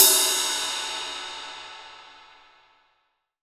Index of /90_sSampleCDs/AKAI S6000 CD-ROM - Volume 3/Ride_Cymbal1/18INCH_ZIL_RIDE